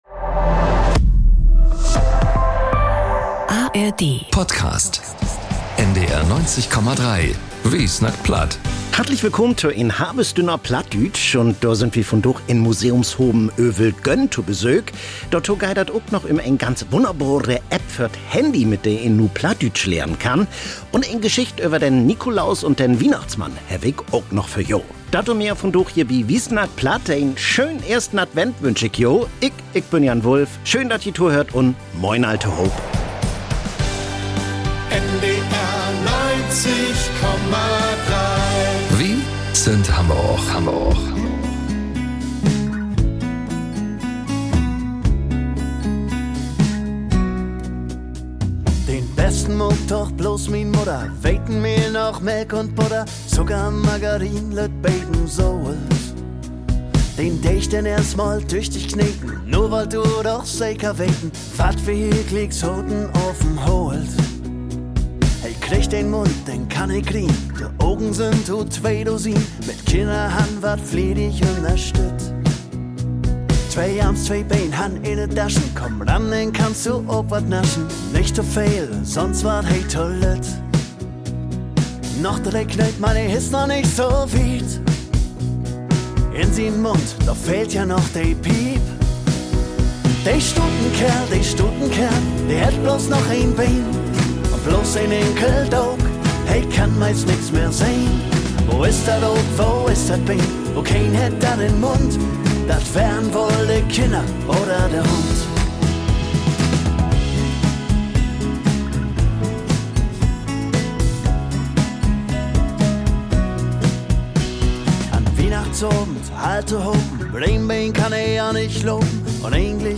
NDR 90,3 bringt de Mudderspraak vun Norddüütschland ok in´t würkliche Leven to´n Klingen - mit Reportagen un Musik op Plattdüütsch/Plattdeutsch.